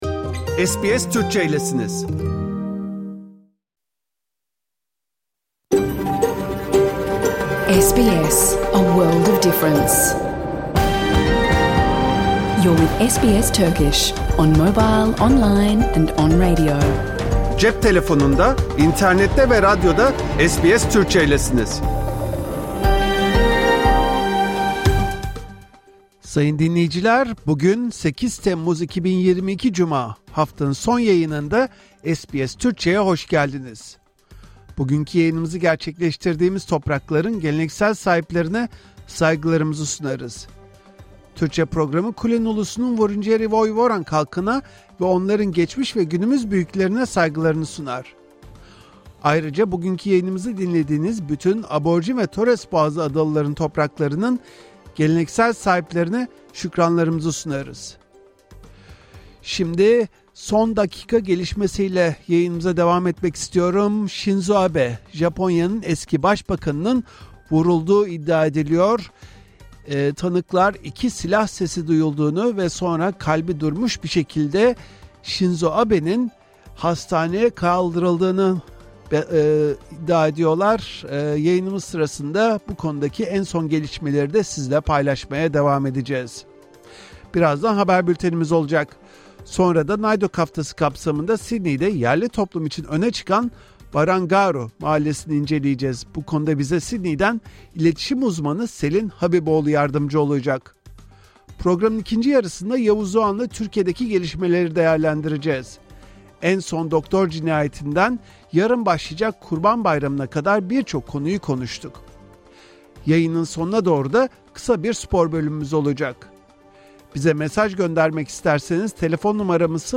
Hafta içi Salı hariç her gün Avustralya doğu kıyıları saati ile 14:00 ile 15:00 arasında yayınlanan SBS Türkçe radyo programını artık reklamsız, müziksiz ve kesintisiz bir şekilde dinleyebilirsiniz.
Öne çıkanlar SBS Türkçe Haber bülteni Avustralya Doğun kıyıları saati ile 12:30’da Japonya’nın eski başbakanı Shinzo Abe’ye yönelik suikast teşebbüsünde bulunuldu.